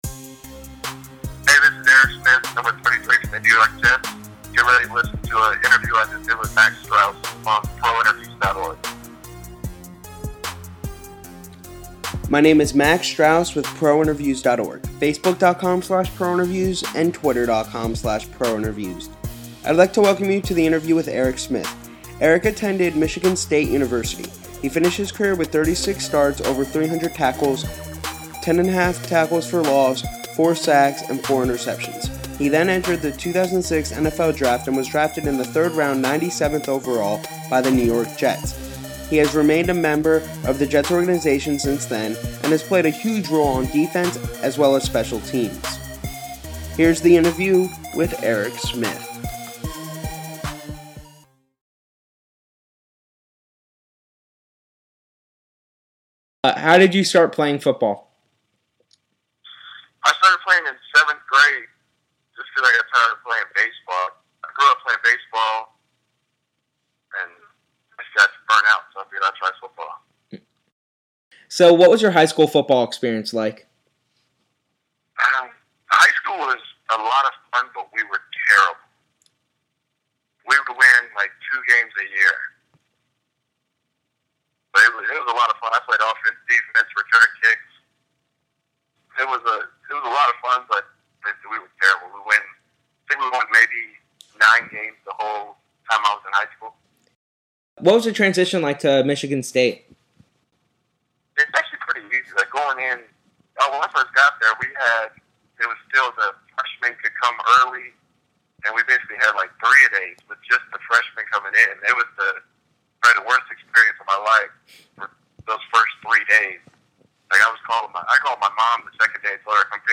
Recent Audio Interviews